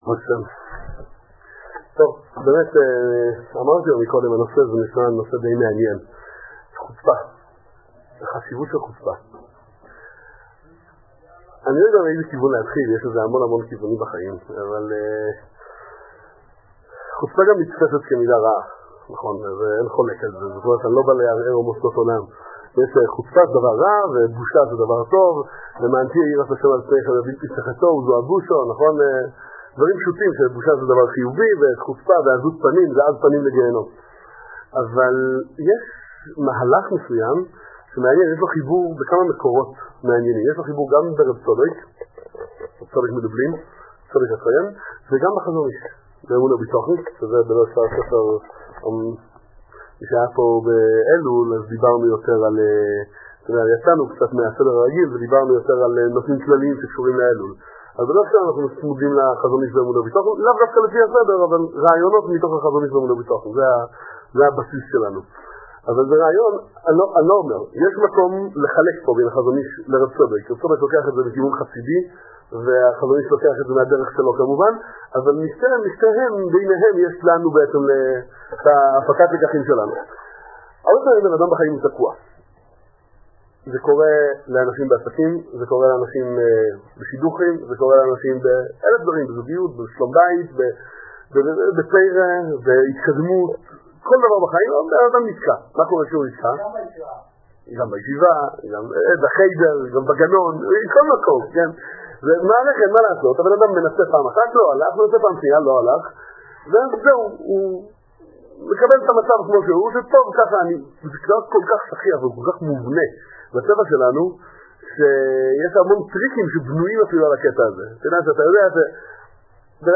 שיעור_בנושא_חוצפה_ה_חשוון_תשעח_24.mp3